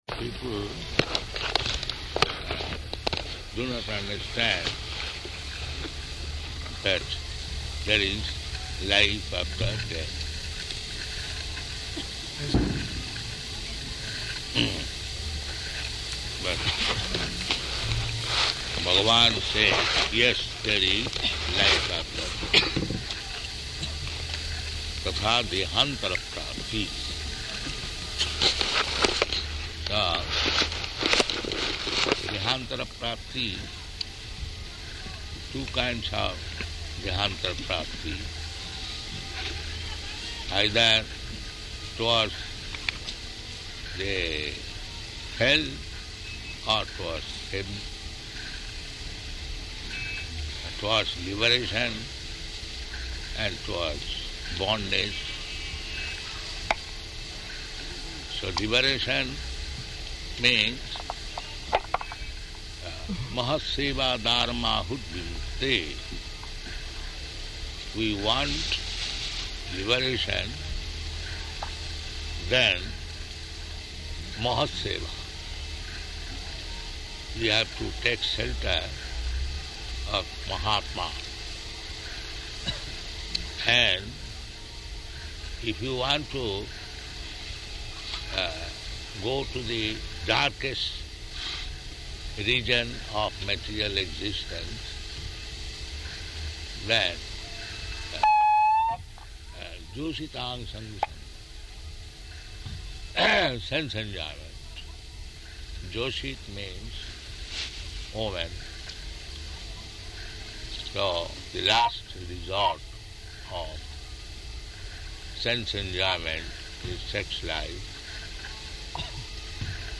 Morning Lecture at Kumbha-melā
Morning Lecture at Kumbha-melā --:-- --:-- Type: Lectures and Addresses Dated: January 15th 1977 Location: Allahabad Audio file: 770115LE.ALL.mp3 Prabhupāda: So people do not understand that there is life after death.